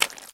High Quality Footsteps
STEPS Swamp, Walk 26.wav